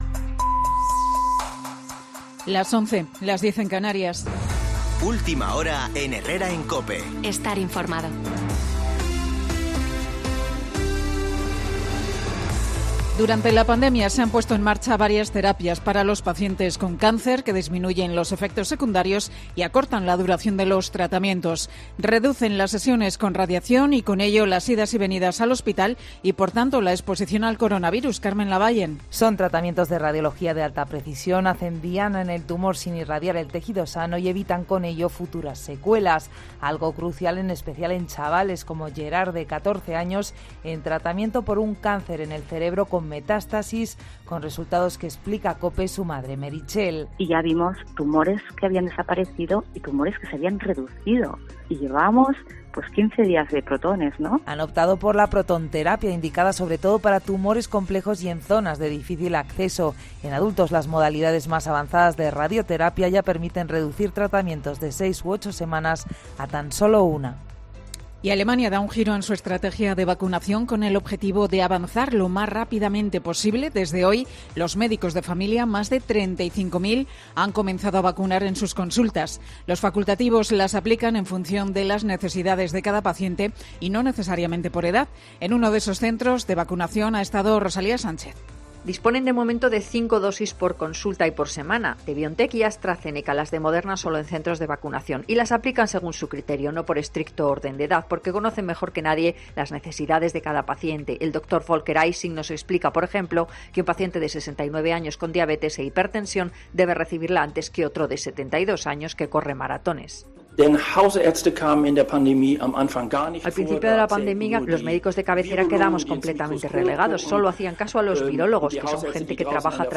Boletín de noticias COPE del 6 de abril de 2021 a las 11.00horas